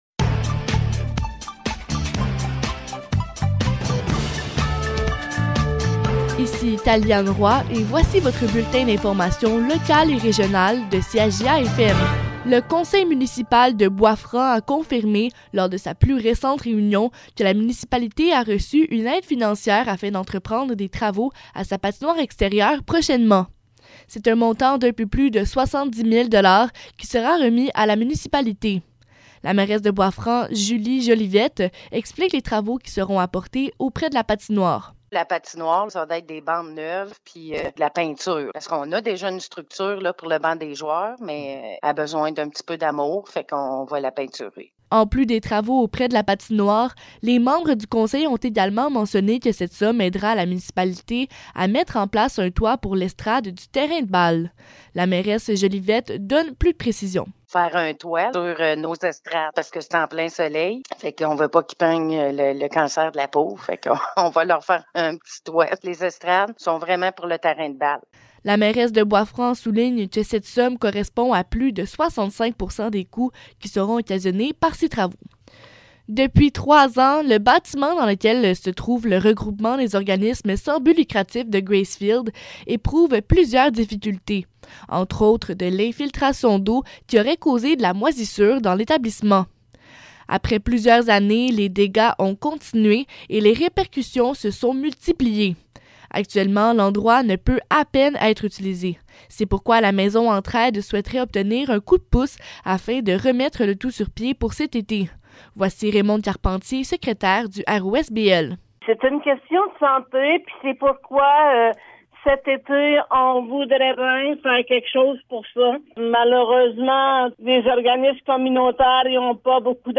Nouvelles locales - 19 avril 2022 - 12 h